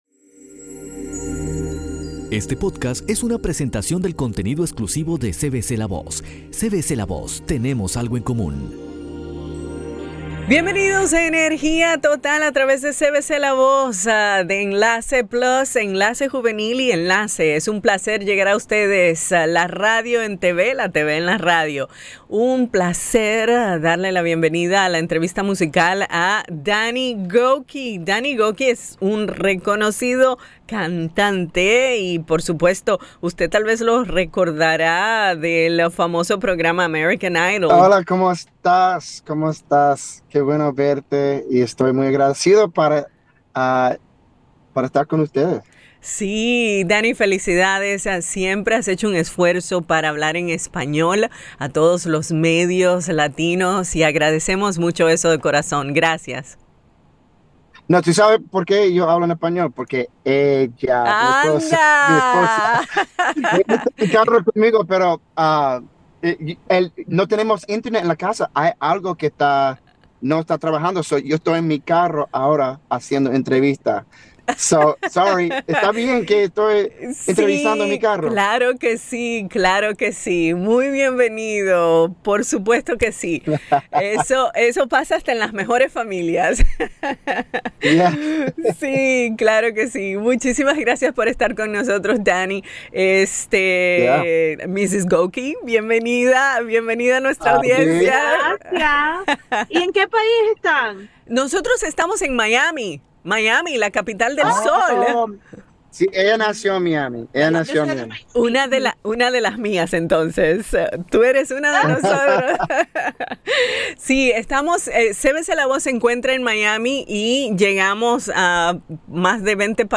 El cantante norteamericano Danny Gokey comparte en una candida entrevista junto a su esposa el éxito de su relación matrimonial y su deseo de unir las diferentes culturas a través de la música.